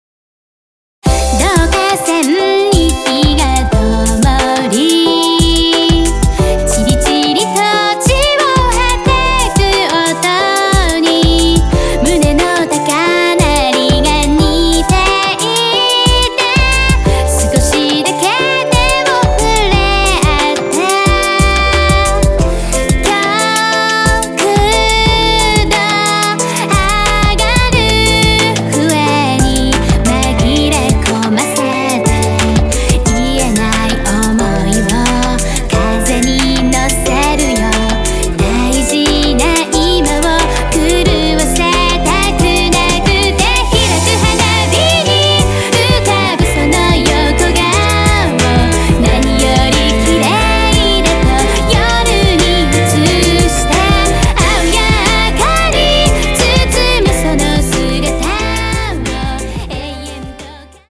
クロスフェードデモ
どんな氷みたいな心も全て溶かしてしまうポップ＆ロック東方フルボーカルアルバム完成！
Piano & strings